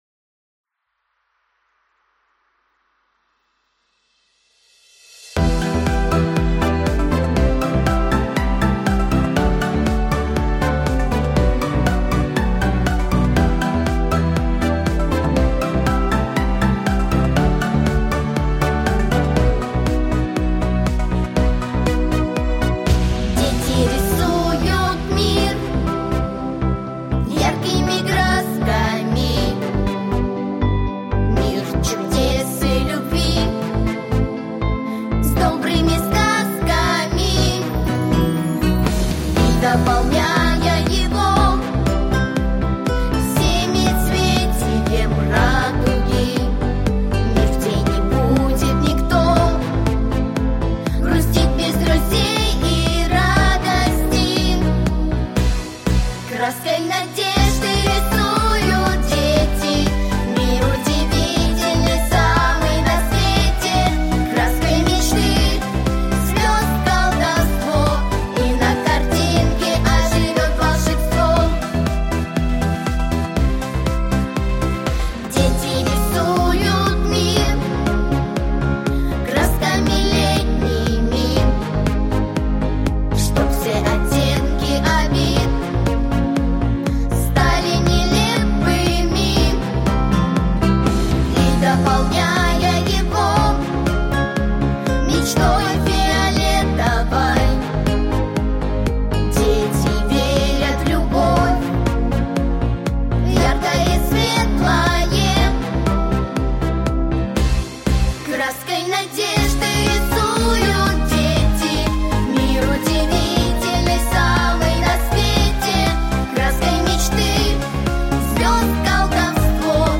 Aудиокнига Дети рисуют мир Автор Виктор Ударцев Читает аудиокнигу Вокальная группа «Дар».